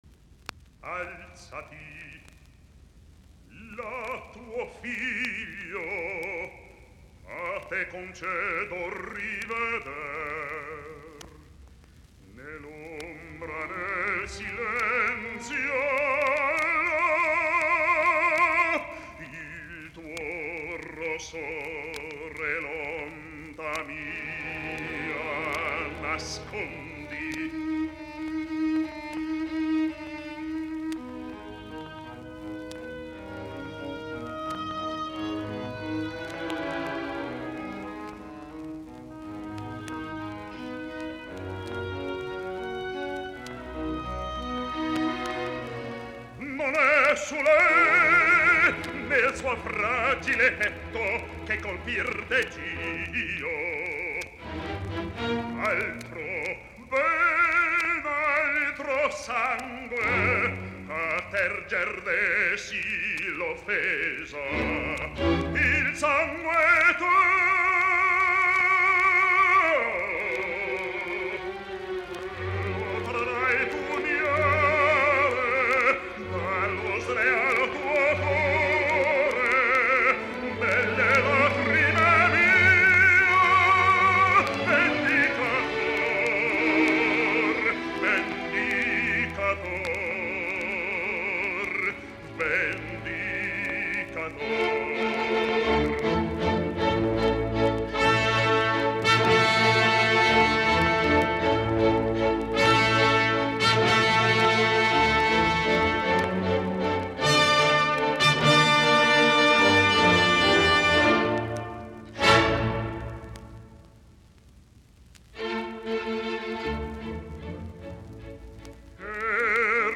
musiikkiäänite
Warren, Leonard ( baritoni ) Verdi baritone arias.